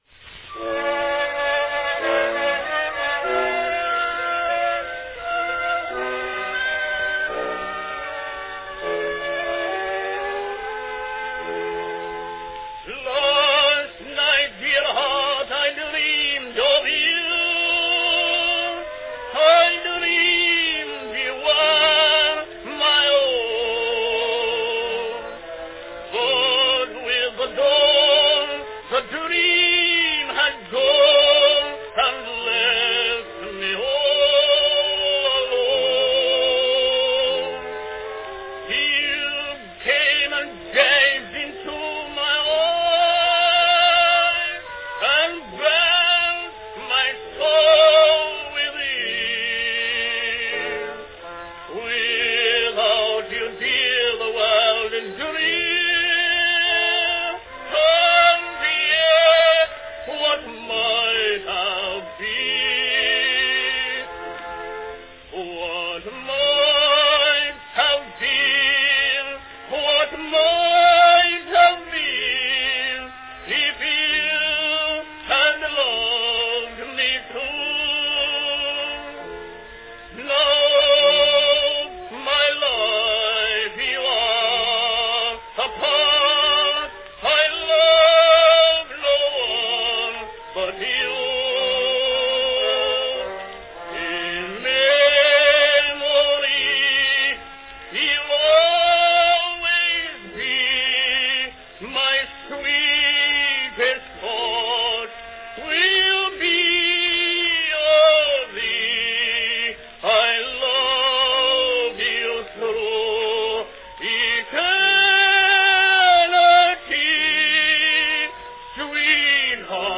Category Sentimental song
Announcement None
A sentimental reverie for high voice